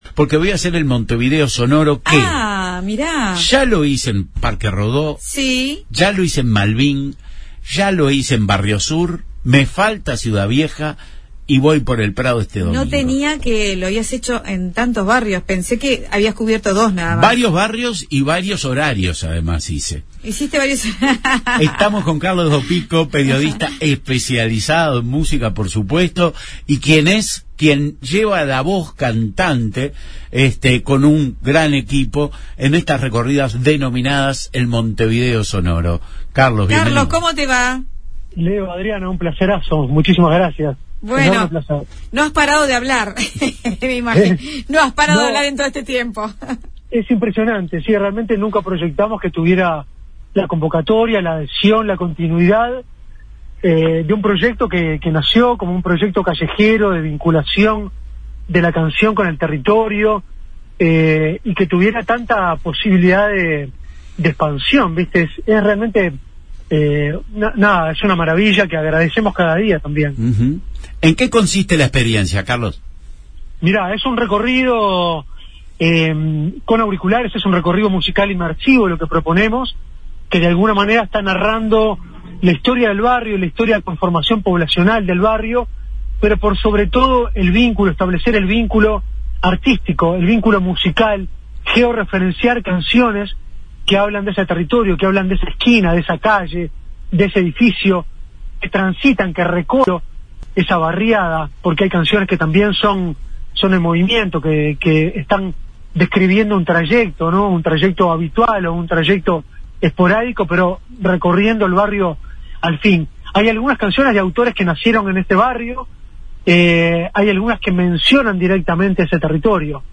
Conversamos